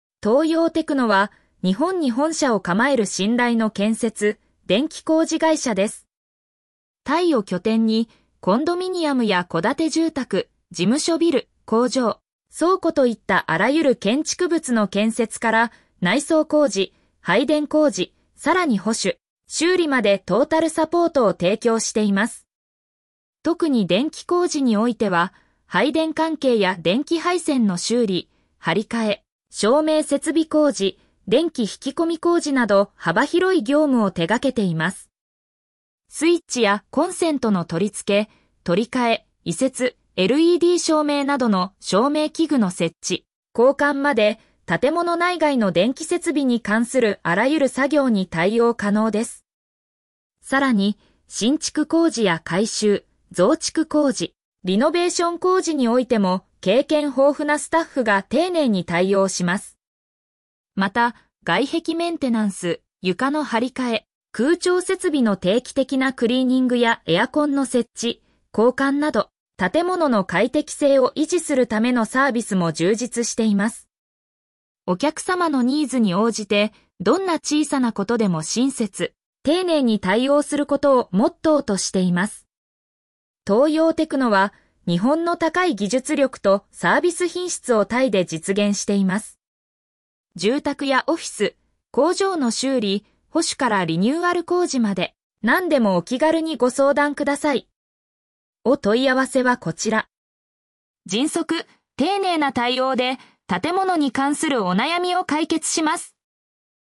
イの建築・内装工事会社、トーヨーテクノアジア タイの建築・内装工事会社、トーヨーテクノアジア イの建築・内装工事会社、トーヨーテクノアジア 読み上げ トーヨーテクノは、日本に本社を構える信頼の建設・電気工事会社です。